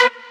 Mainlead_Melody29.ogg